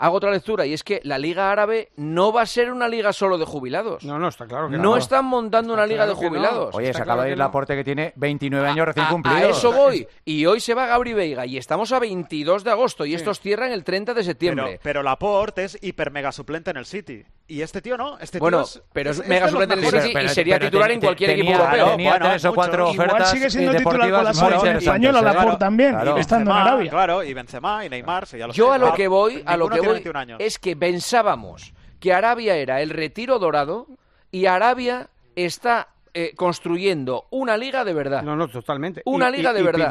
Esta fue la reacción de los miembros del programa de este miércoles cuando conocieron la noticia.